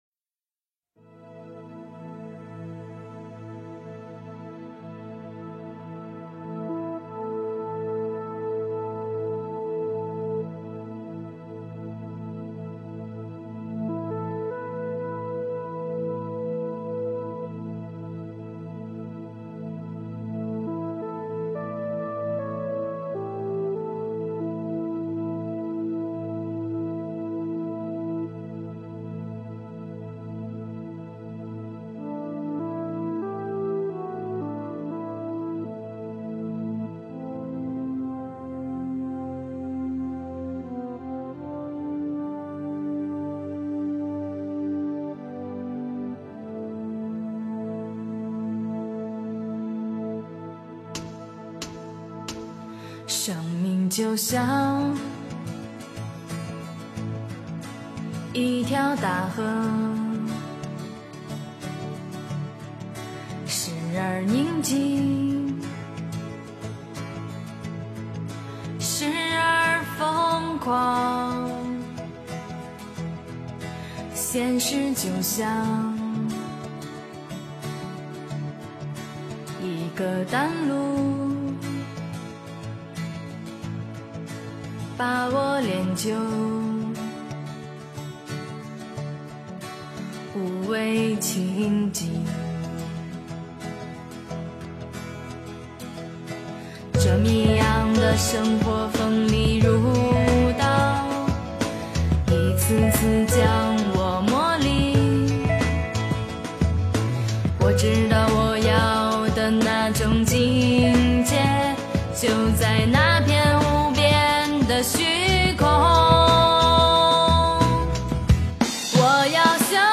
修的更高 诵经 修的更高--佛教音乐 点我： 标签: 佛音 诵经 佛教音乐 返回列表 上一篇： 心是菩提树 下一篇： 一念执着 相关文章 职场15工作与休闲--佛音大家唱 职场15工作与休闲--佛音大家唱...